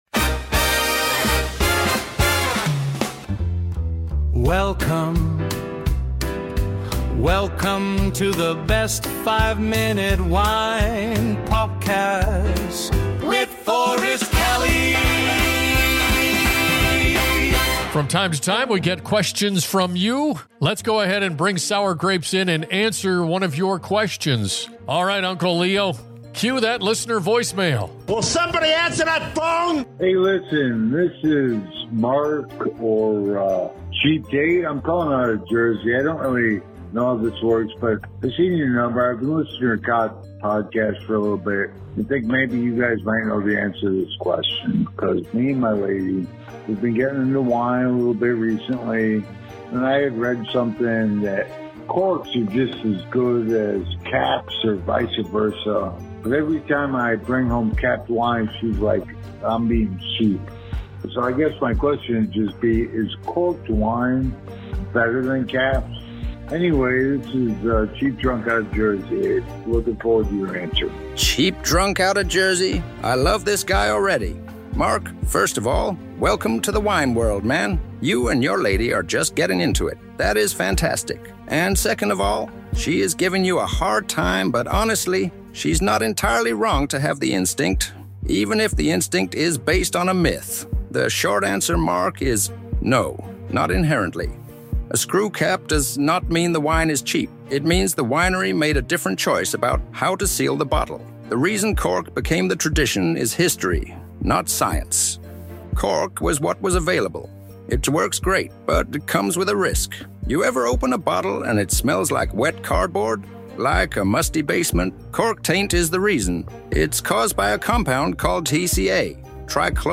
The truth is, screw caps aren't a sign of a cheap wine — they're a sign of a smart winery. From the history of cork to the very real problem of cork taint, this episode breaks down one of wine's most persistent myths in classic Best 5 Minute Wine Podcast fashion: fast, funny, and completely on your side.